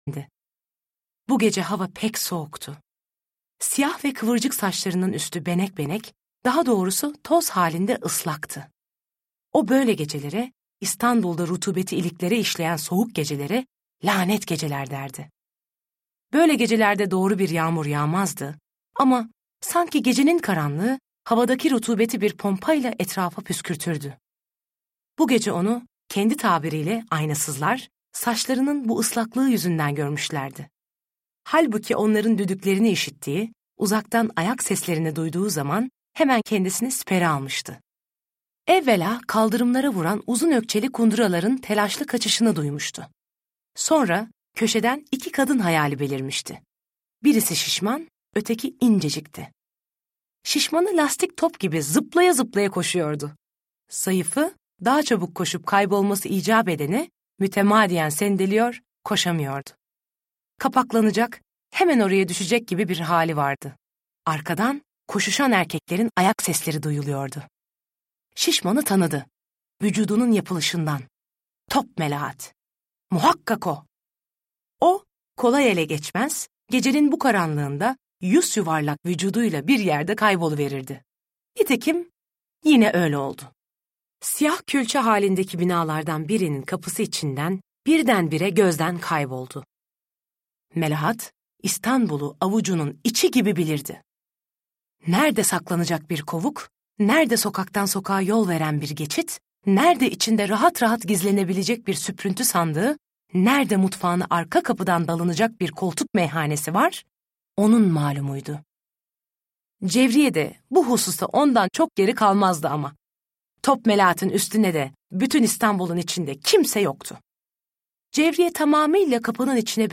Fosforlu Cevriye’yi ”Son Fosforlu” Ayça Varlıer’in seslendirmesiyle dinleyebilirsiniz.